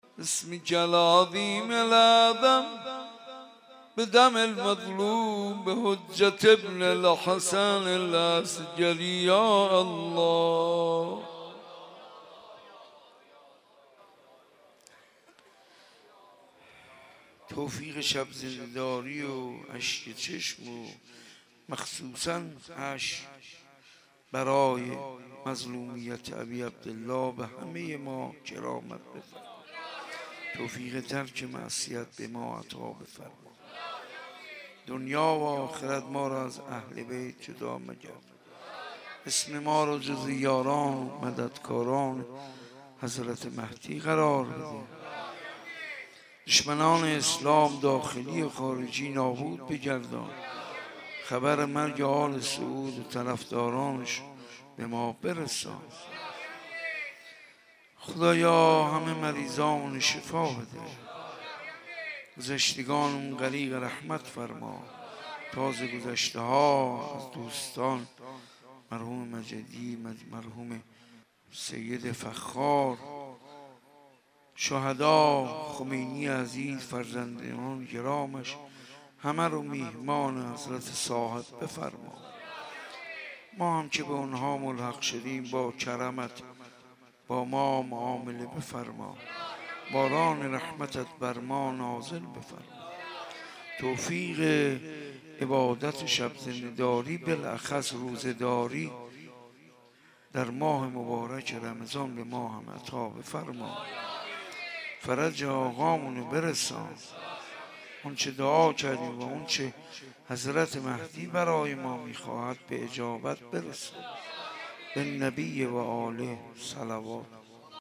در حسینیه بیت الزهرا(س)
مولودی خوانی
به مدیحه سرایی پرداختند.